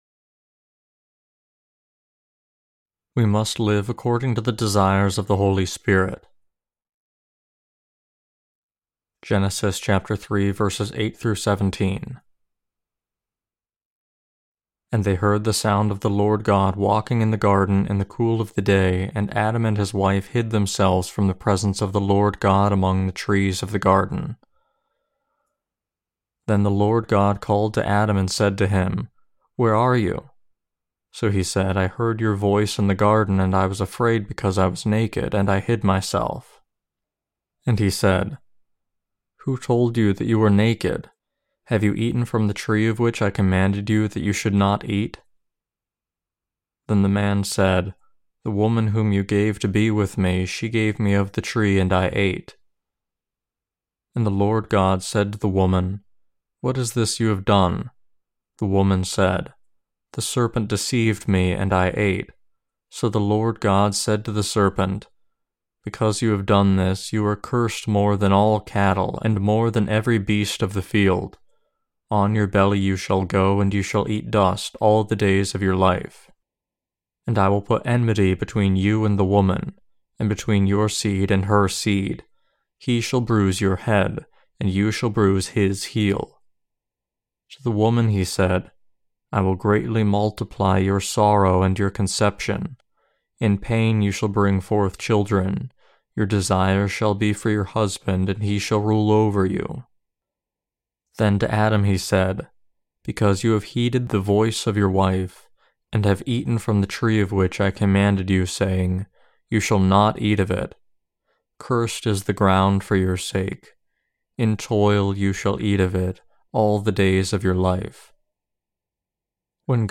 Sermons on Genesis (II) - The Fall of Man and The Perfect Salvation of God Ch3-9.